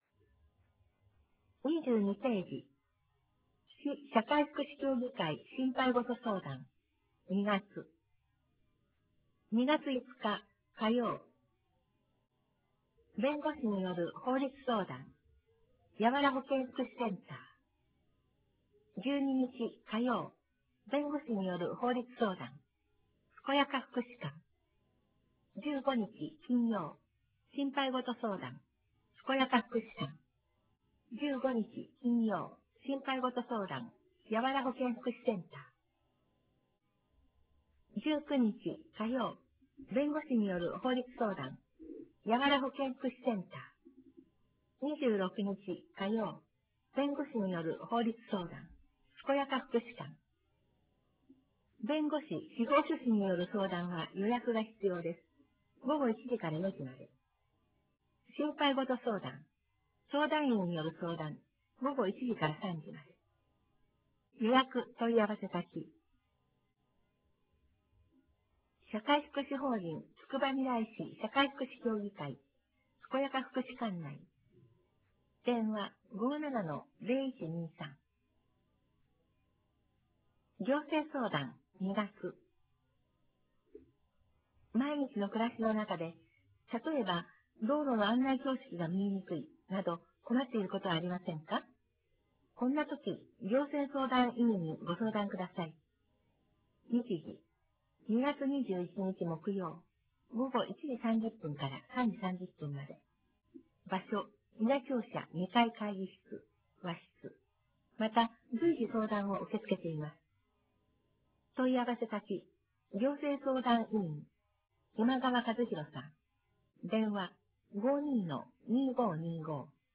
音声による広報つくばみらい(2013年2月号)